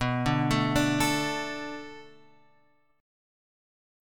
B Minor 7th